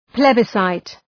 Προφορά
{‘plebı,saıt}
plebiscite.mp3